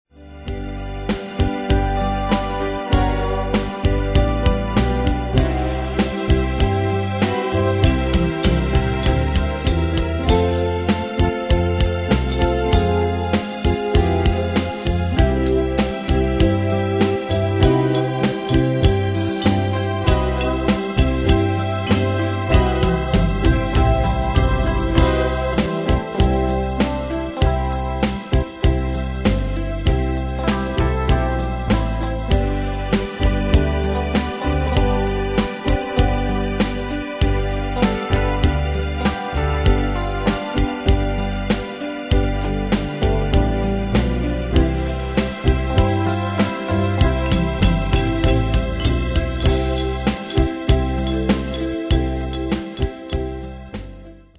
Style: Country Année/Year: 1982 Tempo: 98 Durée/Time: 5.04
Danse/Dance: Rhumba Cat Id.
Pro Backing Tracks